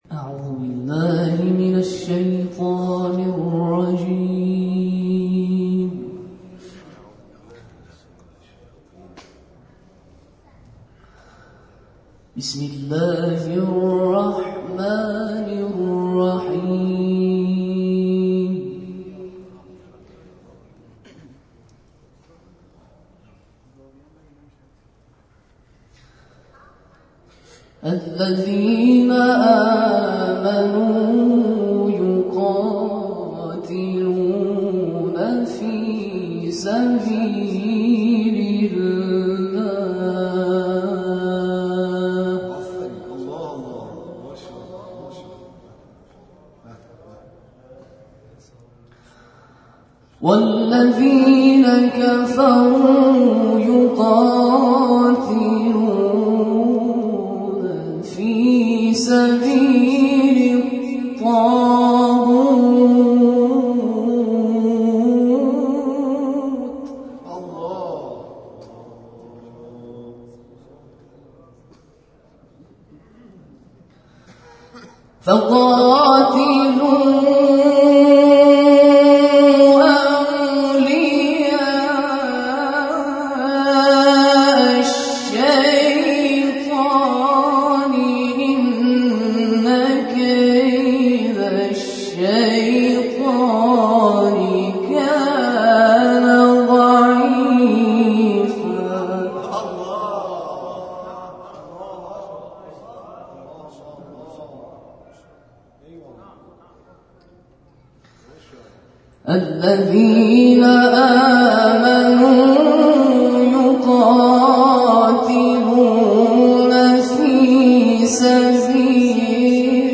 در ادامه تلاوت‌های این جلسه ارائه می‌شود.